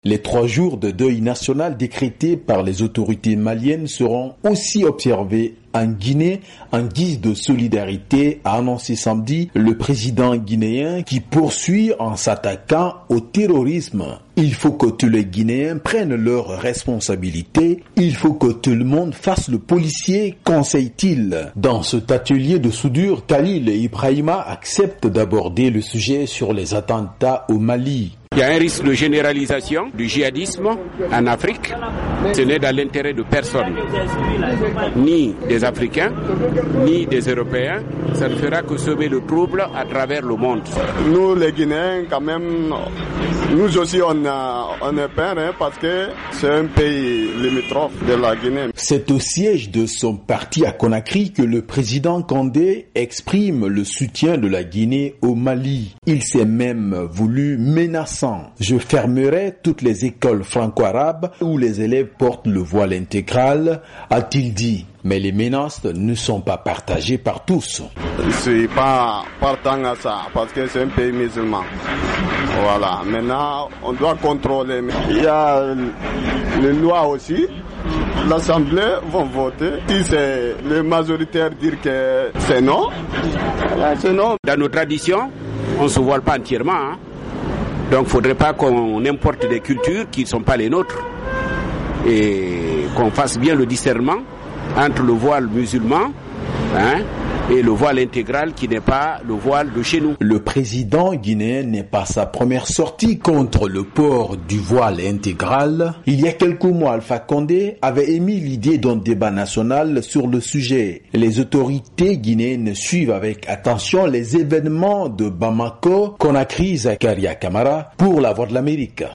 Correspondance